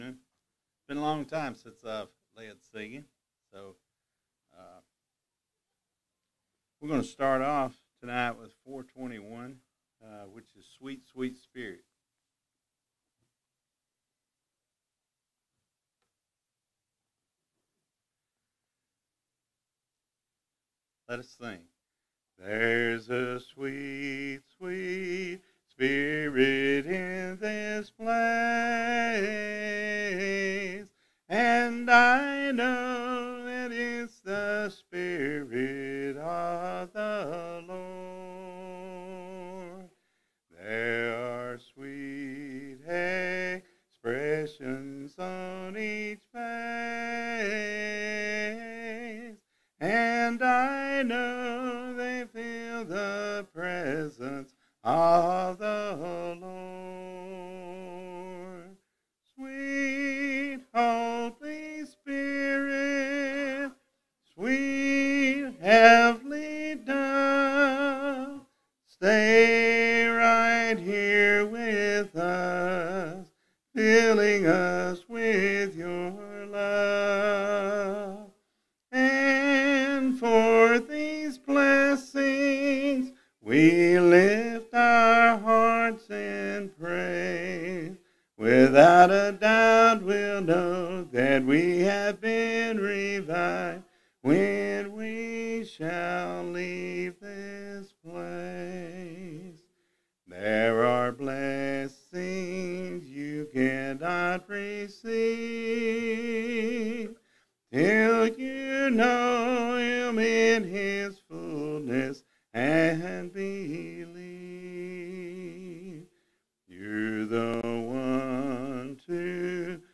Revelation 13:10 (English Standard Version) Series: Sunday PM Service